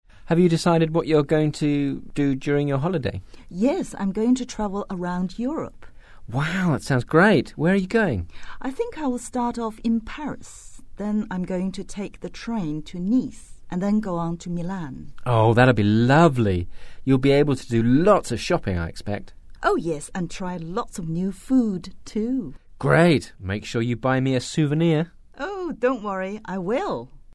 英语初学者口语对话第05集：你想好了假期做什么了吗？